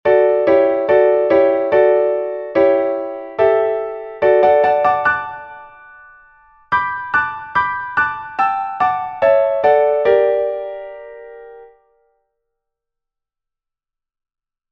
一応、違いが分かるように、とてもゆっくりとしたテンポでそれぞれの音源を作ってみたので、聴き比べてみてください。
前の２つの小節は、どちらも同じです。変わっているのはその後ろの小節、メロディに付けられている和音が全然違っています。